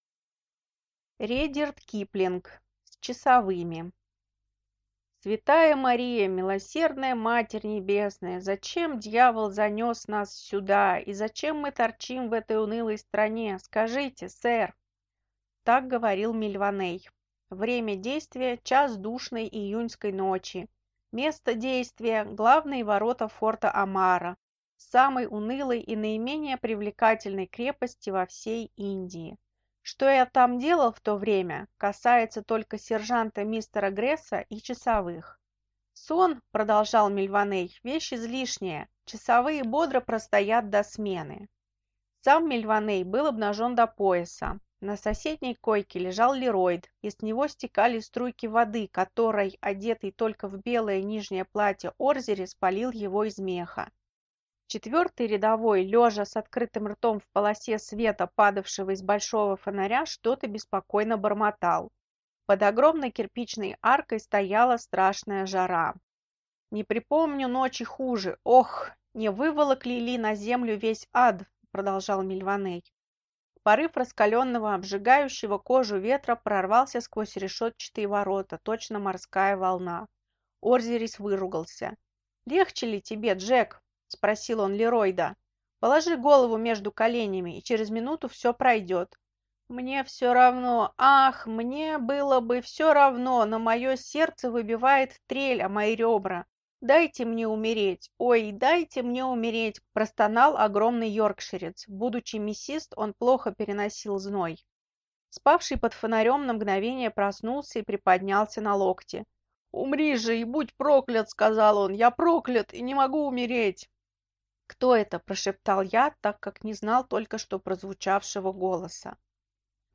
Аудиокнига С часовыми | Библиотека аудиокниг